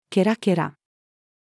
cackling-sound-of-shrill-laughter-female.mp3